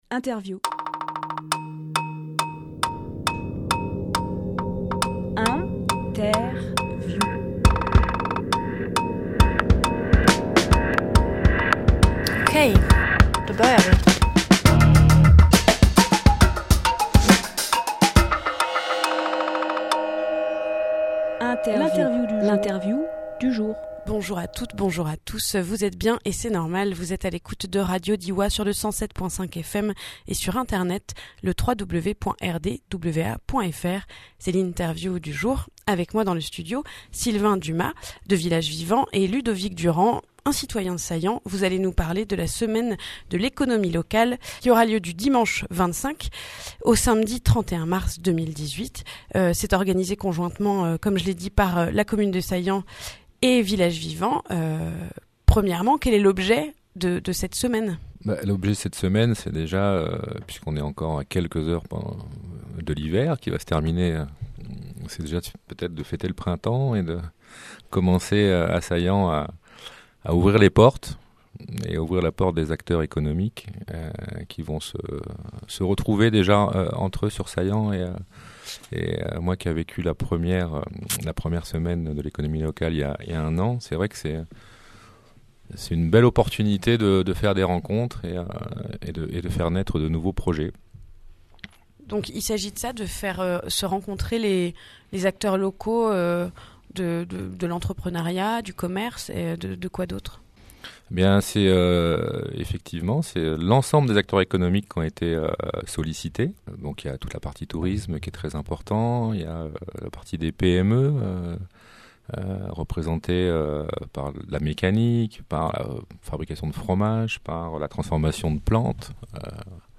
Emission - Interview La semaine de l’économie locale : 25-31 mars à Saillans !
Lieu : Studio RDWA